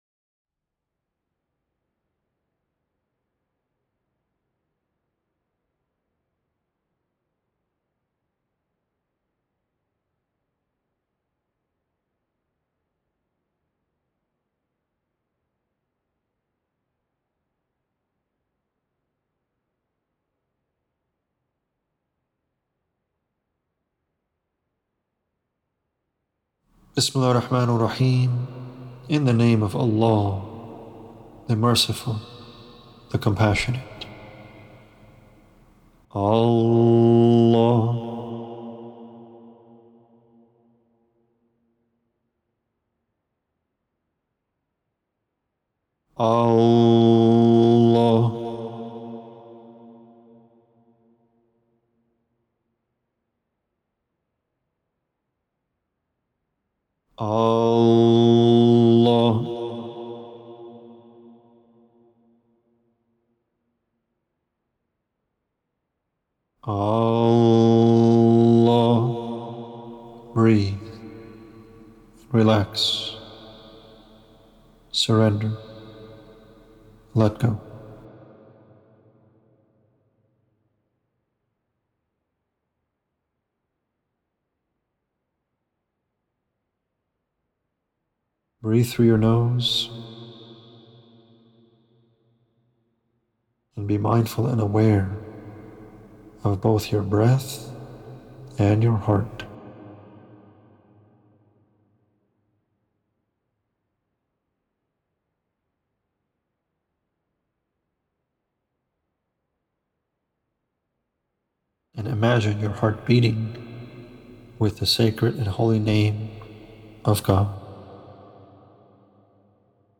To skip the introductory content in the video about and jump straight into the meditation practice, please go to the timestamp 8:41.